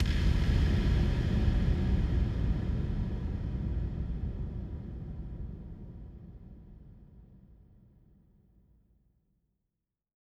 VTS1 Selection Kit 140BPM Impact FX.wav